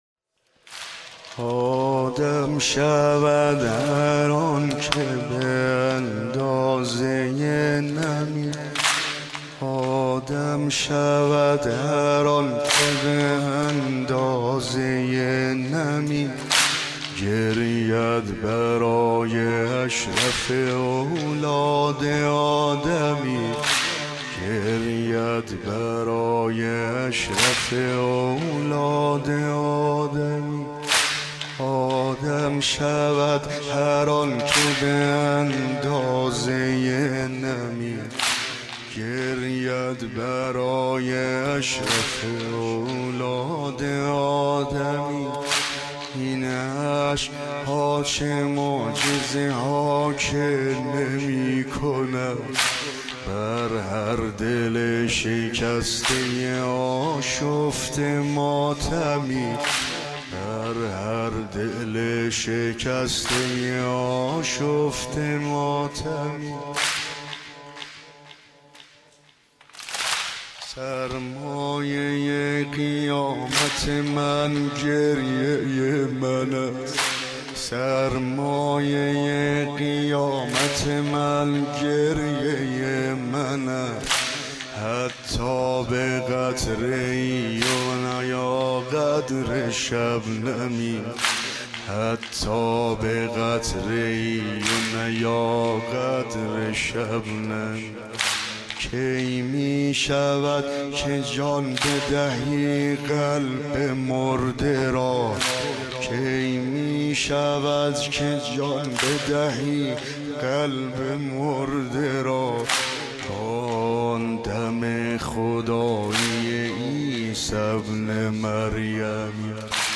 این اشک ها چه معجزه ها که نمی کند | واحد | ورود به محرم